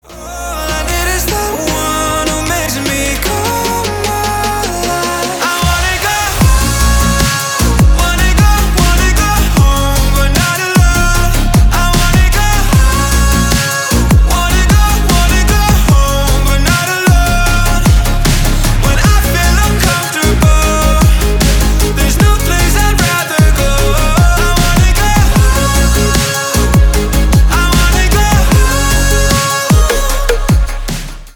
• Качество: 320 kbps, Stereo
Танцевальные
клубные